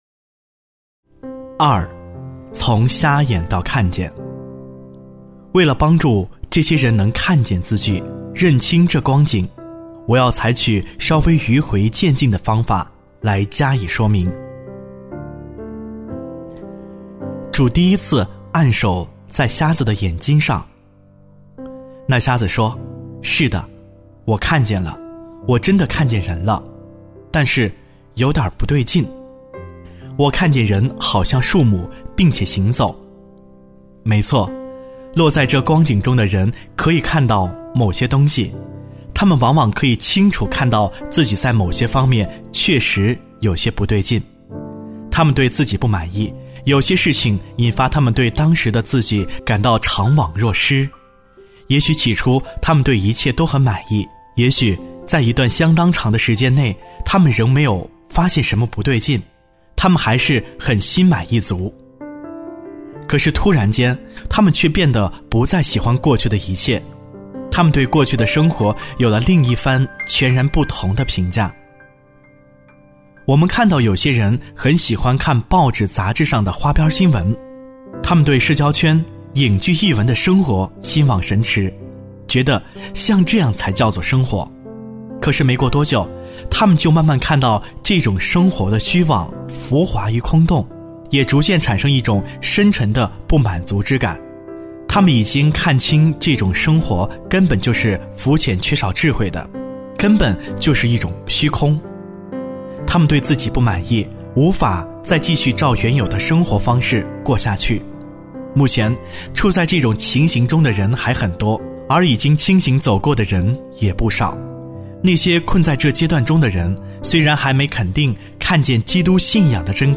首页 > 有声书 | 灵性低潮录 | 灵性生活 > 灵性低潮录 第五章 你看见了吗？